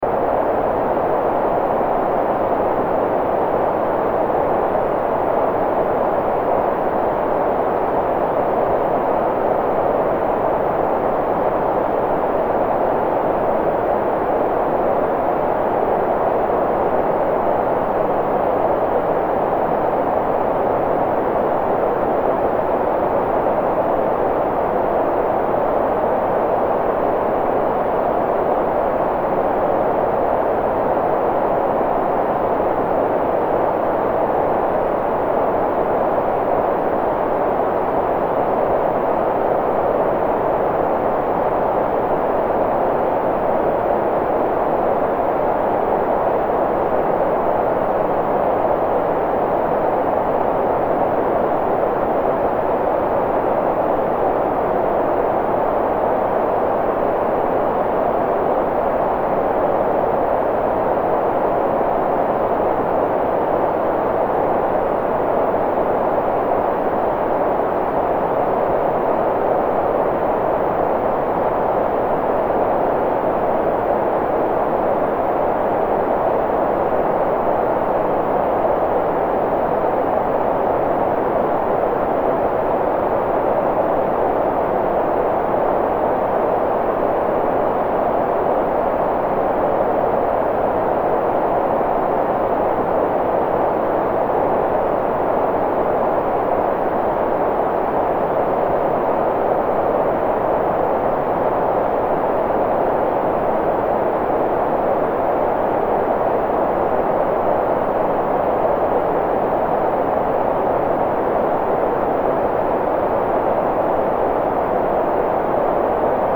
Green-noise-for-deep-sleep.mp3